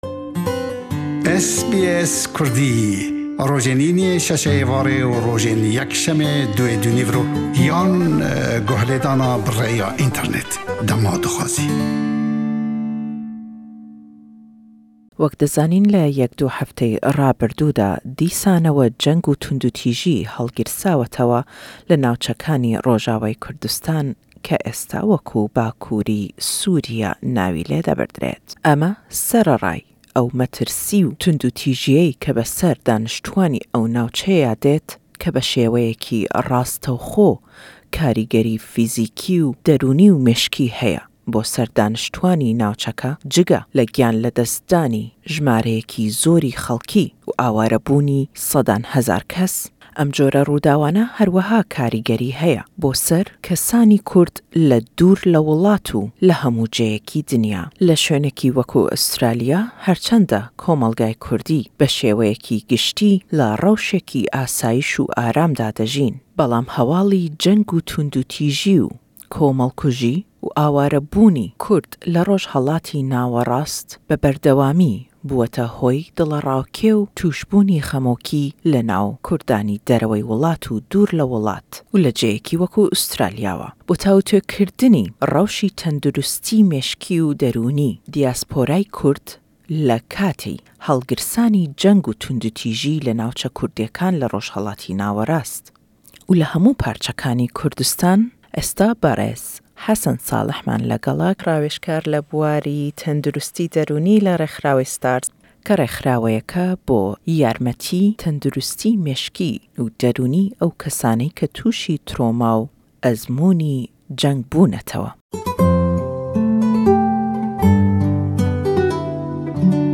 Le em hevpeyvîne